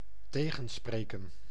Käännös Konteksti Ääninäyte Substantiivit 1. gedoe {n} amerikanenglanti 2. zever {m} amerikanenglanti 3. tegenspreken amerikanenglanti Verbit 4. tegenspreken amerikanenglanti 5. opspelen amerikanenglanti